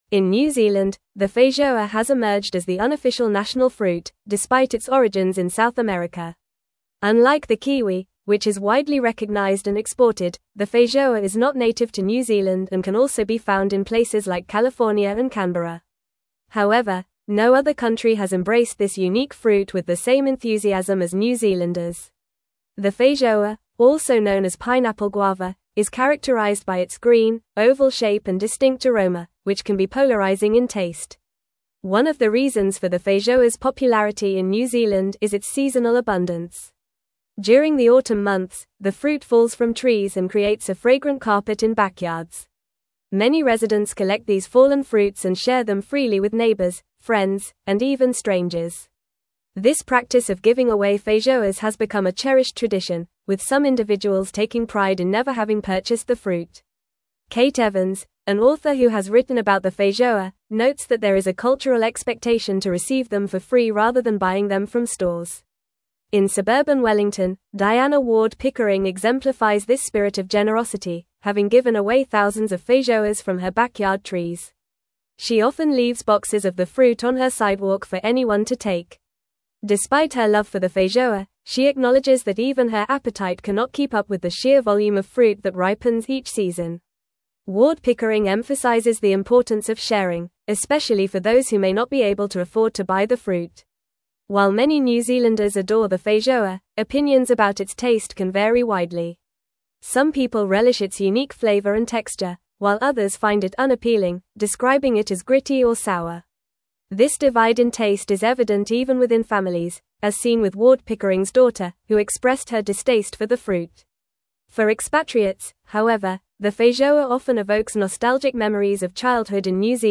Fast
English-Newsroom-Advanced-FAST-Reading-Feijoa-New-Zealands-Beloved-Autumn-Fruit-Tradition.mp3